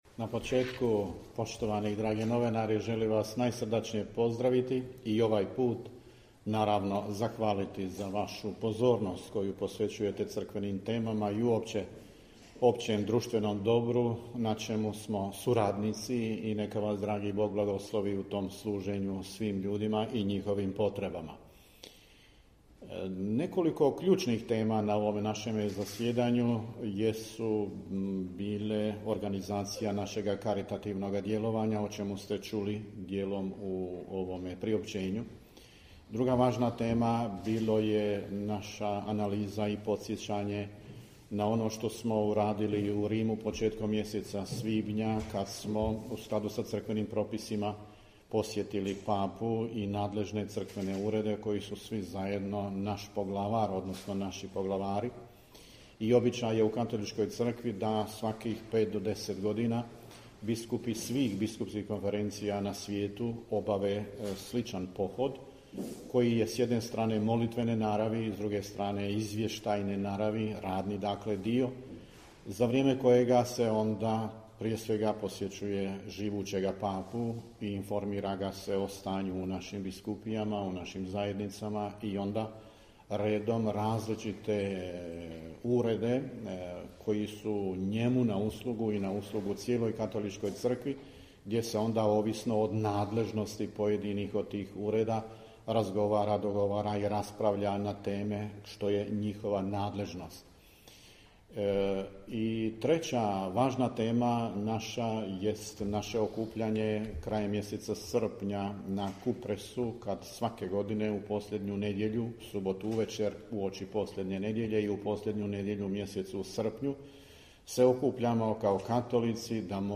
Audio: Nadbiskup Vukšić i biskup Majić na konferenciji za novinstvo govorili o 90. redovitom zasjedanju BK BiH